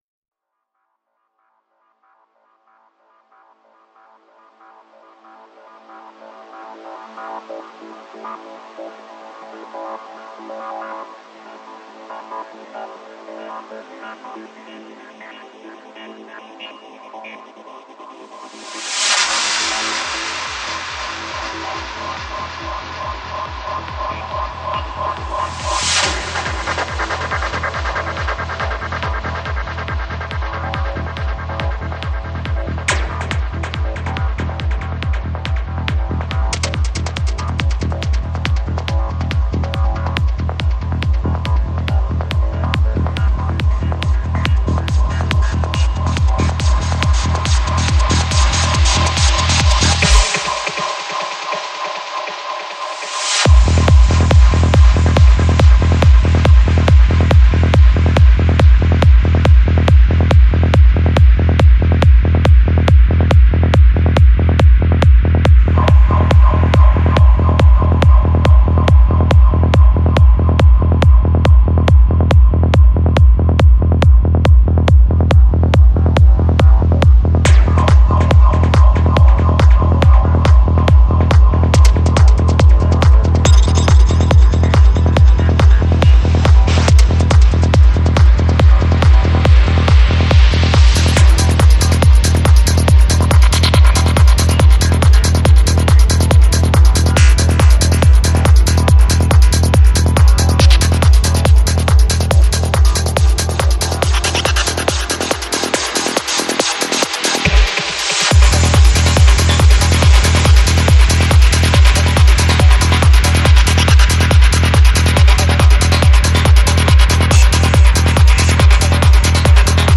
Жанр: Trance
21:53 Альбом: Psy-Trance Скачать 9.31 Мб 0 0 0